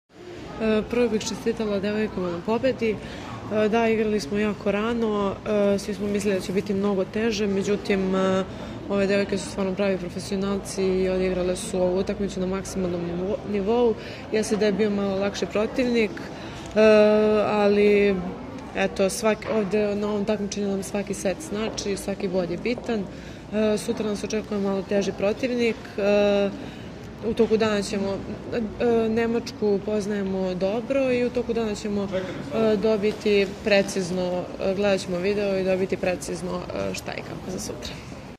Izjava